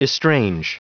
Prononciation du mot estrange en anglais (fichier audio)
Prononciation du mot : estrange